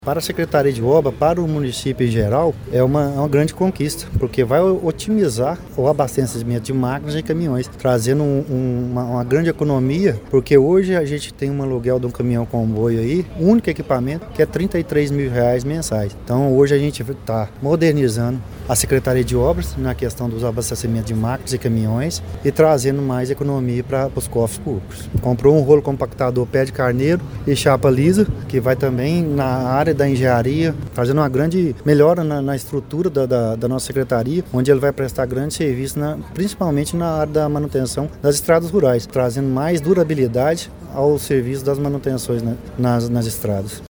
Já o secretário municipal de Obras Públicas e Infraestrutura, André Lara Amaral, ressaltou que a novidade contribui para modernizar a operação da pasta e melhorar a manutenção das estradas rurais: